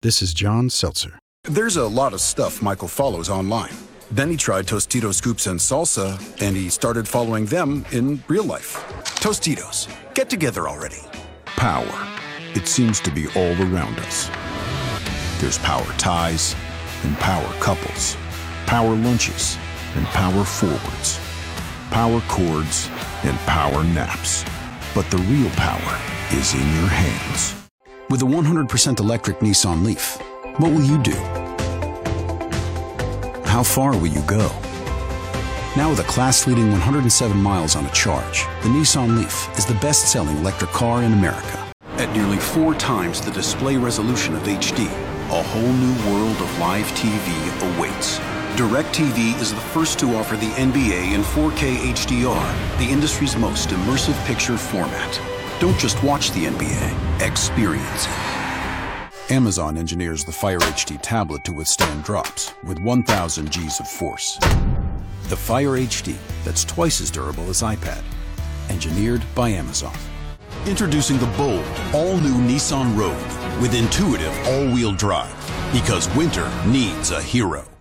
French, American south, American Northeast
Young Adult
Middle Aged